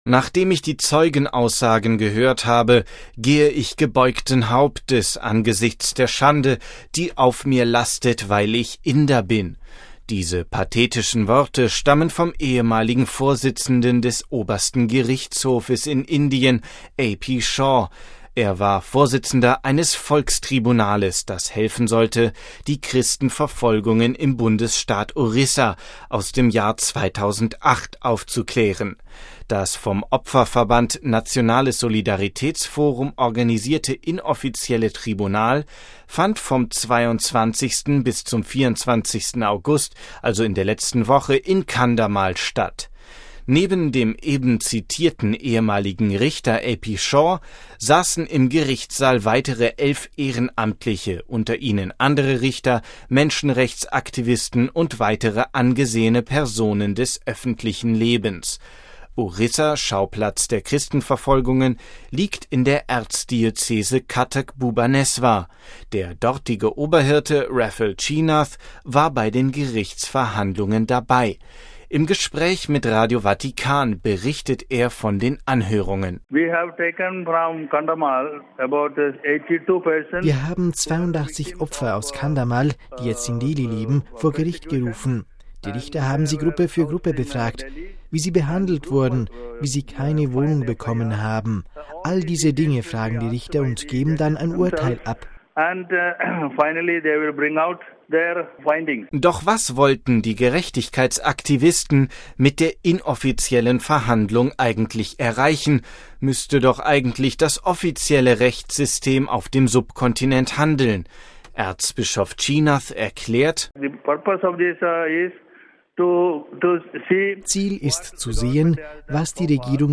Erzbischof Cheenath erklärt:
Im Gespräch mit Radio Vatikan erläutert die besondere Bedeutung dieses Tages für den Prozess der Versöhnung in Indien: